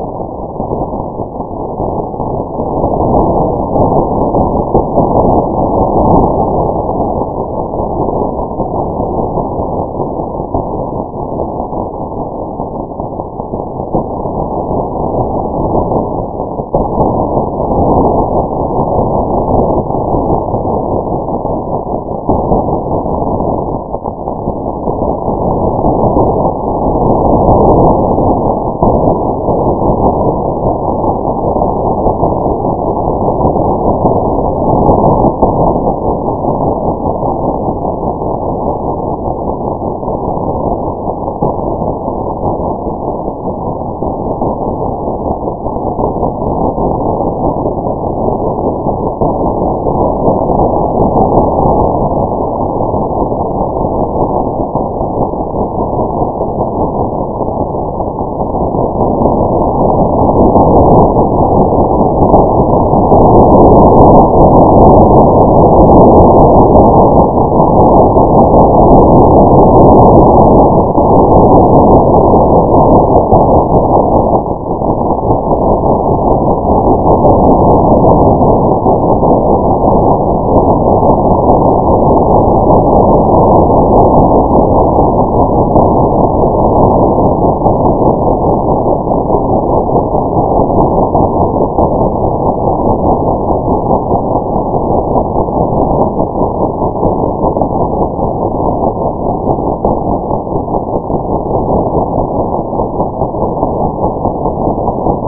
The display below converts the log return series to sound using the Mathematica function ListPlay.  When you listen to the data, you will notice the crescendo of amplitude seen in November, August, and January as well as the mechanical repetitive sound related to the intraday cycle.